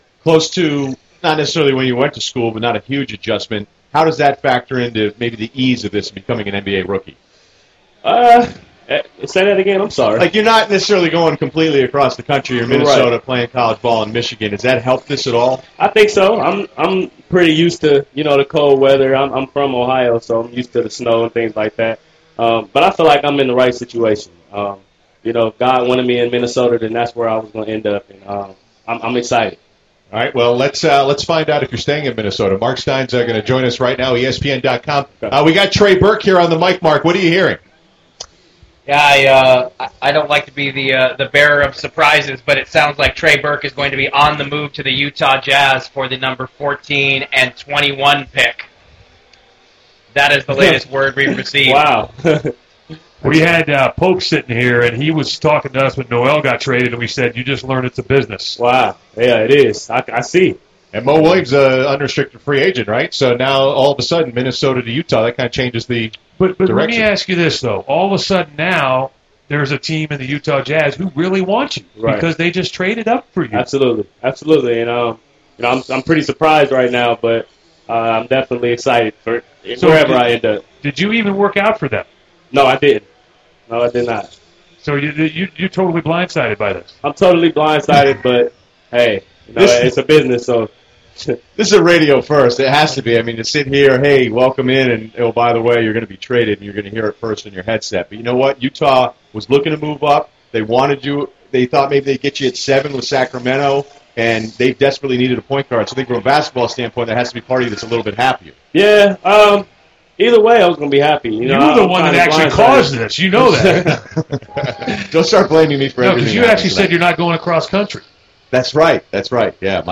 Trey Burke learns he is traded during radio interview
Burke started the interview saying he was excited to play with the T-Wolves, and less than three minutes later he learned on air that he was traded.